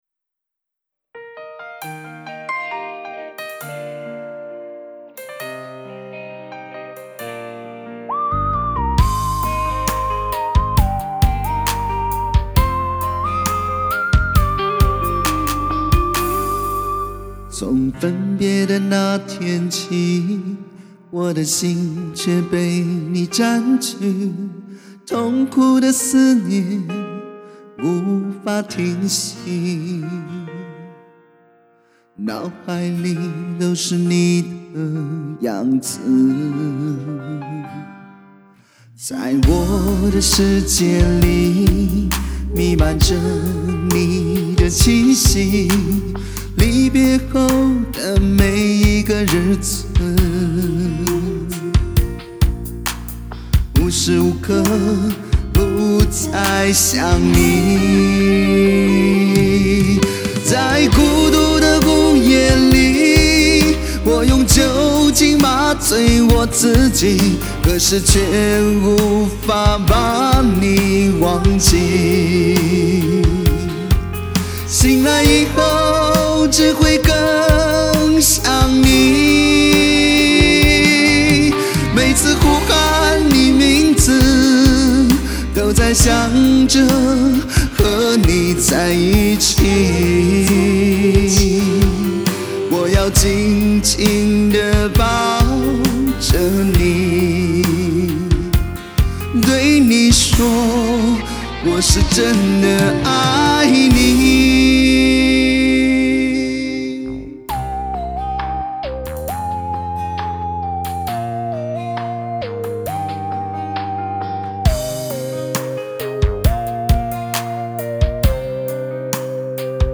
具有磁性的嗓音